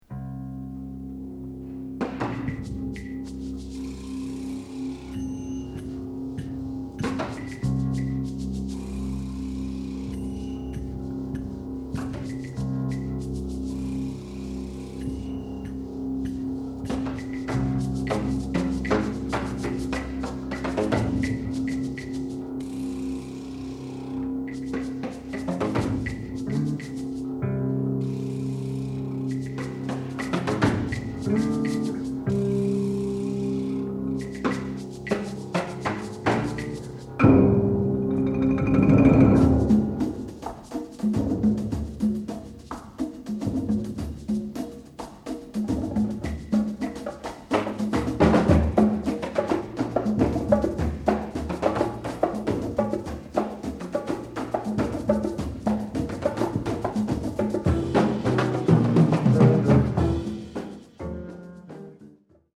Genre: Solo with Percussion Ensemble
# of Players: 7 + Solo Drum Set & Winds
Vibraphone (3-octave) & Small Shaker
Marimba (4.3-octave) & Small Shaker
Percussion 2 (small shaker, PVC pipe, chimes)
Percussion 3 (small shaker, congas)
Timpani (4 drums) & Small Shaker
Electric Bass